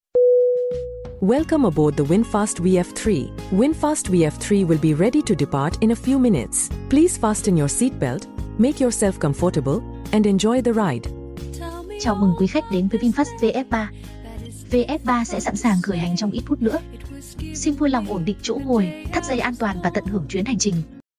Âm Thanh Chào Khởi Động Carplay VinFast VF3 (Tiếng Việt, Giọng nữ)
Thể loại: Tiếng chuông, còi
Description: Trải nghiệm âm thanh chào khởi động Carplay VinFast VF3 với giọng nữ mềm mại, sống động, cả Tiếng Việt và Tiếng Anh. Âm thanh mở đầu thân thiện, đầy phong cách, biến mỗi lần bật xe thành khoảnh khắc đặc biệt như lên chuyến bay VF3 Airline.
am-thanh-chao-khoi-dong-carplay-vinfast-vf3-tieng-viet-giong-nu-www_tiengdong_com.mp3